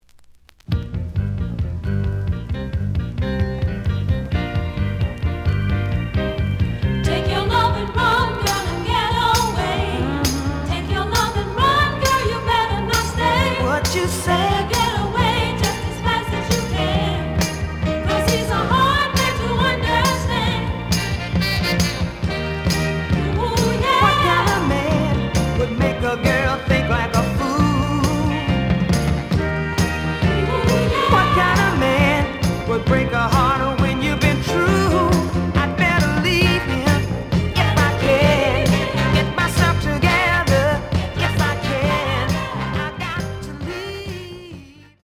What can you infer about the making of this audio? The audio sample is recorded from the actual item. Edge warp.